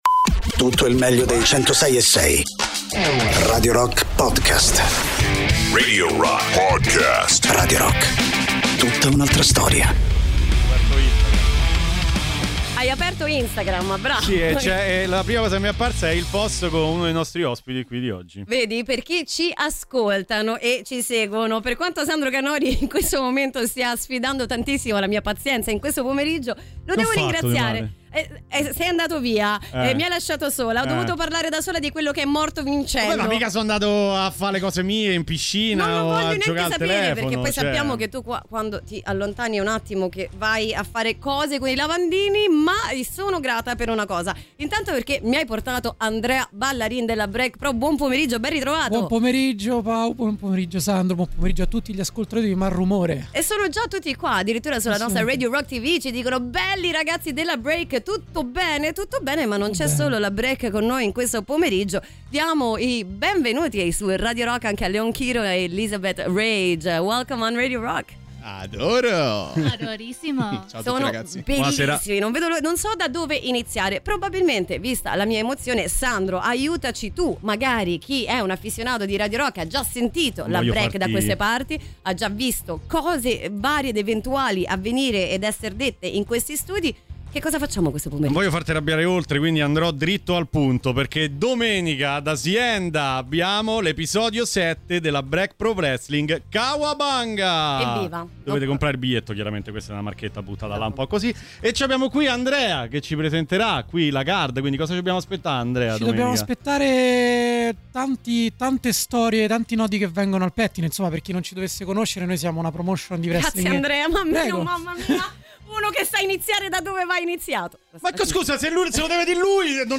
Intervista: BreakPro Wrestling (05-09-25)